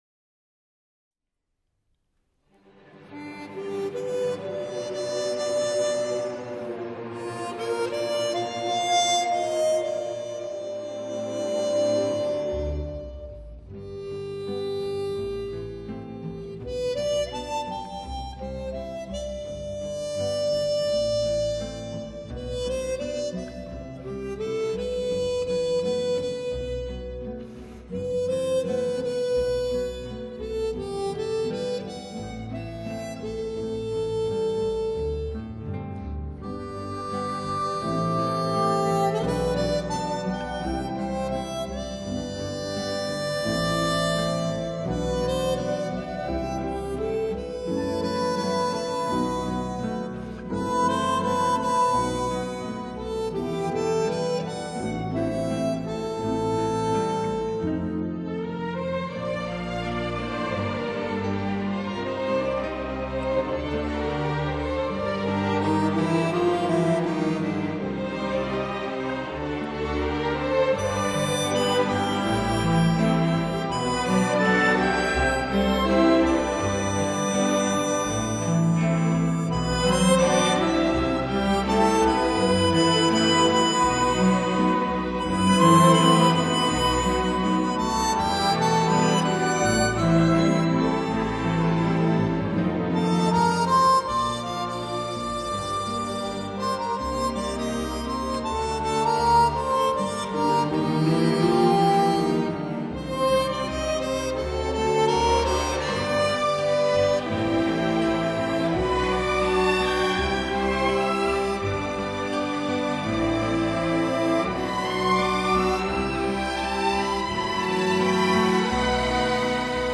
將口琴清亮雅致的高貴音色烘托得淋漓盡致